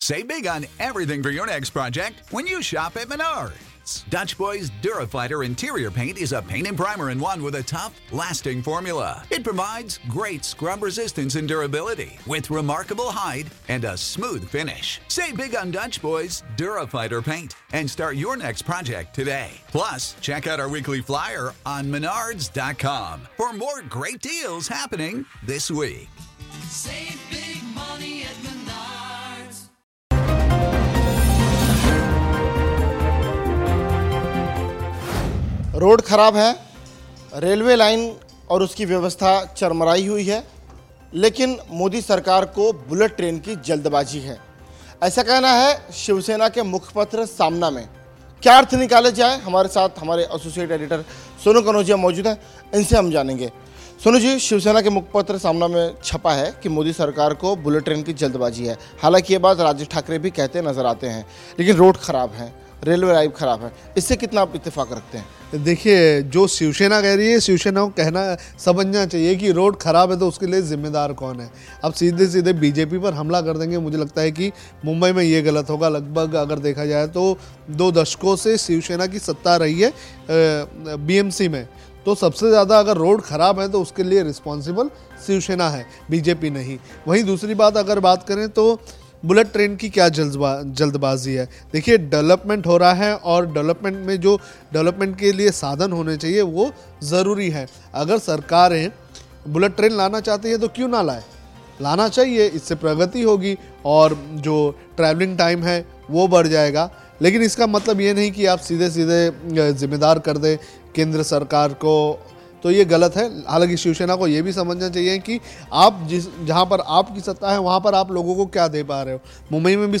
न्यूज़ रिपोर्ट - News Report Hindi / शिवसेना उद्धव ठाकरे और राज ठाकरे लोकसभा चुनाव से पहले एकसाथ आए, पर कैसे ?